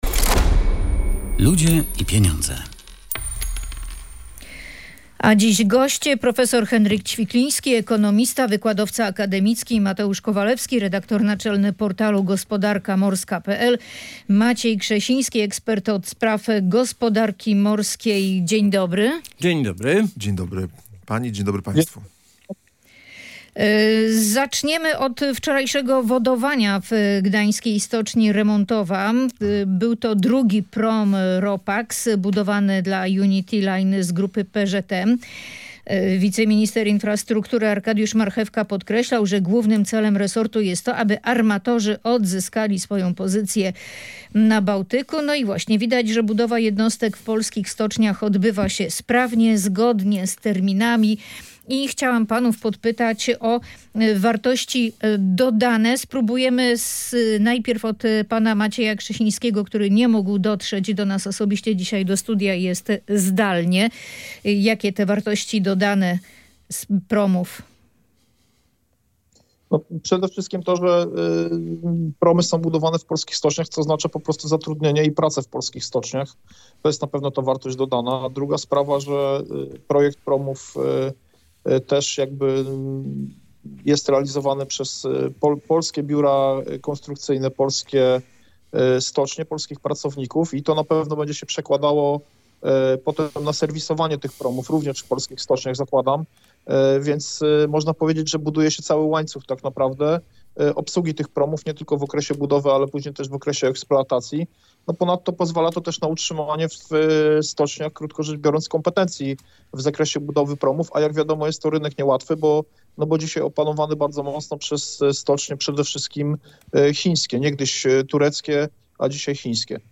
Gośćmi audycji „Ludzie i Pieniądze” byli ekspert od spraw gospodarki morskiej